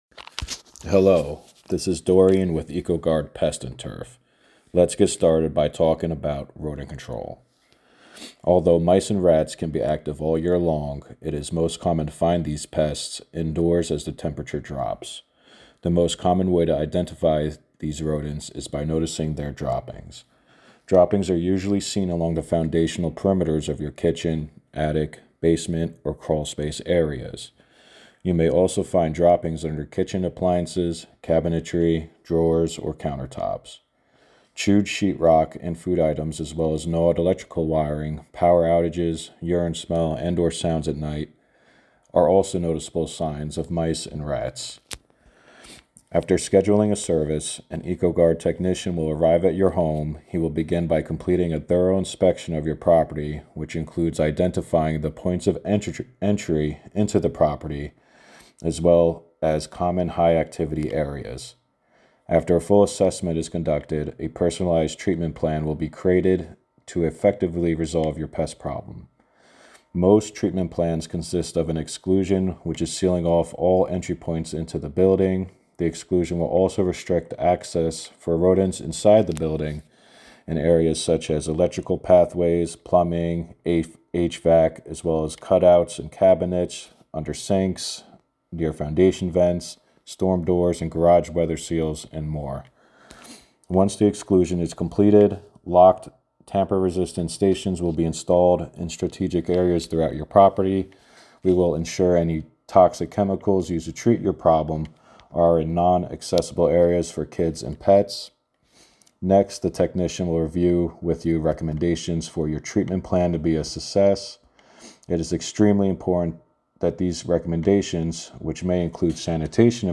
Rodent Control Audio Explainer